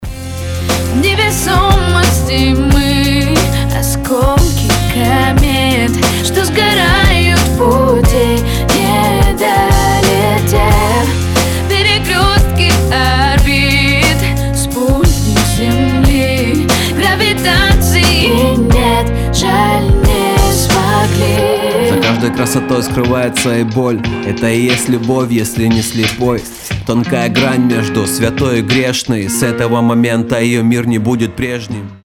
• Качество: 320, Stereo
лирика
русский рэп
дуэт